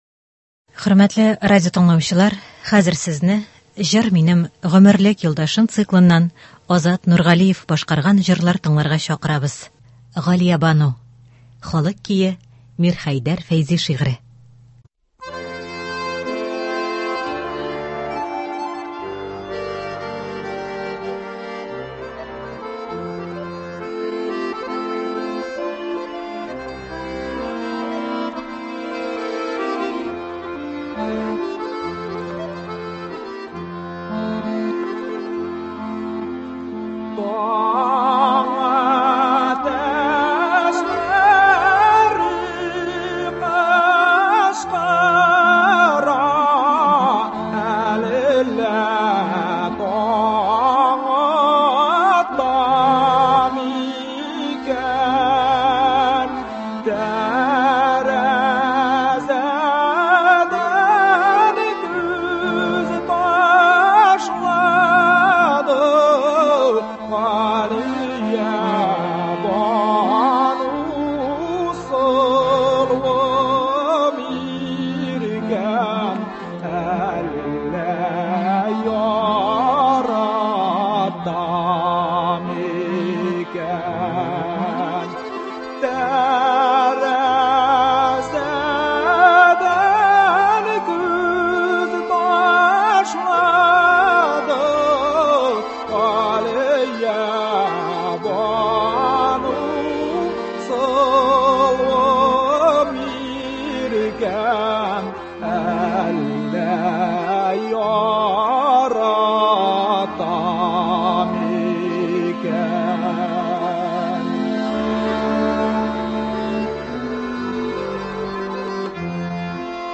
Үзешчән башкаручылар чыгышы.
Концерт (03.06.24)